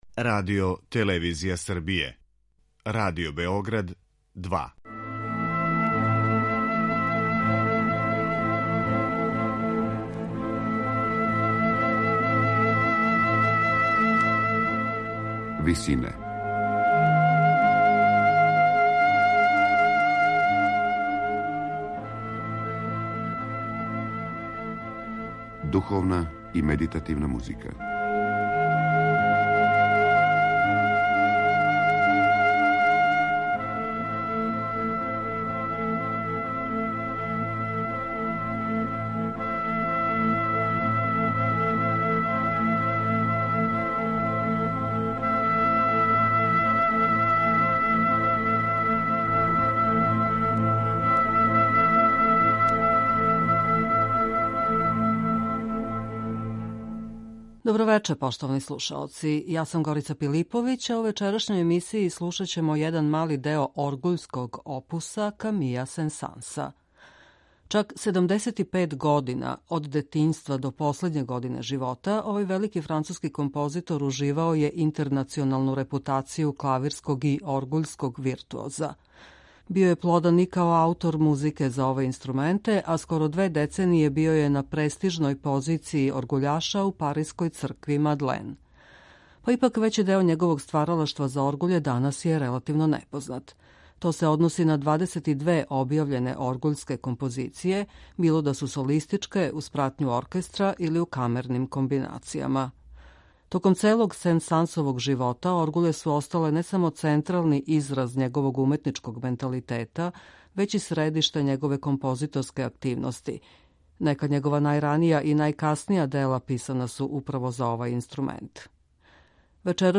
Вечерас ћемо прво емитовати Прелудијум и фугу у Ес-дуру, трећи пар у опусу 99, и лагани став, Адађо, из Сен-Сансове Треће, тзв. Оргуљске симфоније, у обради само за оргуље.